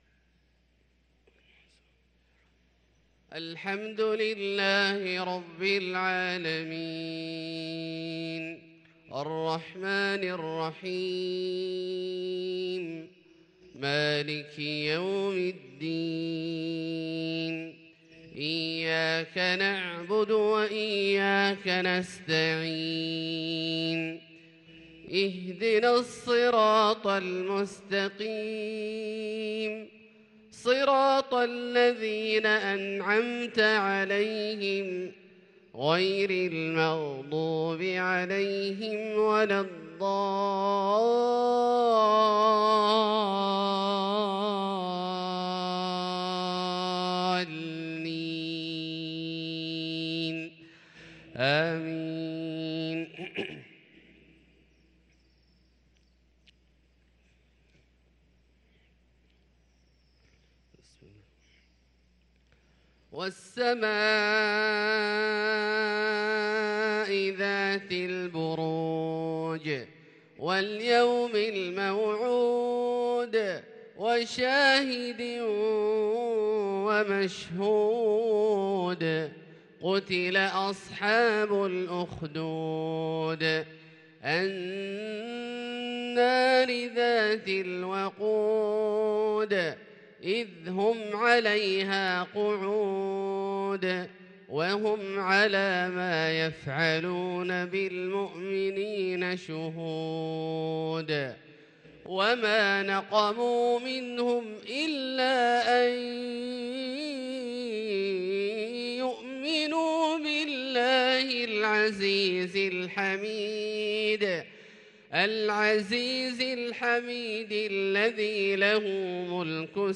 صلاة الفجر للقارئ عبدالله الجهني 21 جمادي الأول 1444 هـ